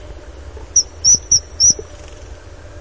Martin pescatore
Una serie di note cinguettanti, quali uno stridulo e ben udibile (ma non sonoro) ‘crii’, ‘ci-chi’ o simili. Il canto di corteggiamento è un melodioso insieme di note sibilanti e gorgheggianti.
Martin_Pescatore.mp3